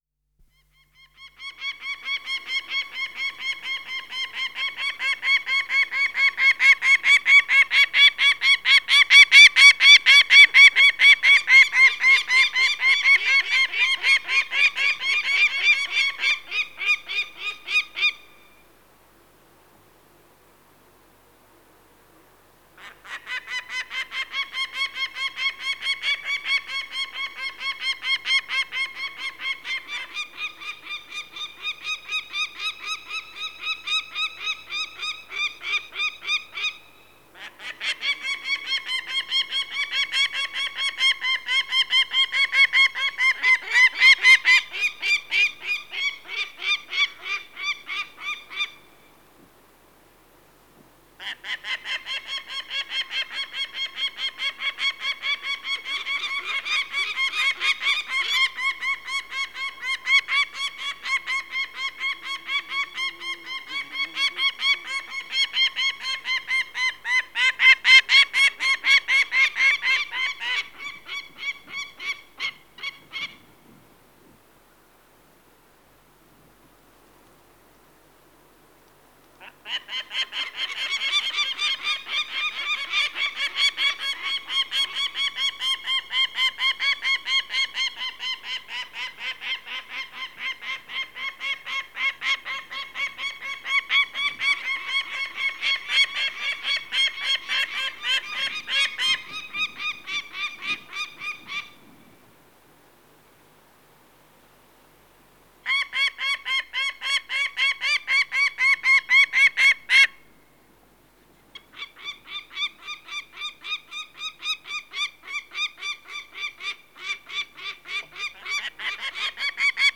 Wanderfalke Ruf
• Der typische Ruf des Wanderfalken klingt wie ein scharfes „kak-kak-kak“.
Wanderfalke-Ruf-Voegel-in-Europa.wav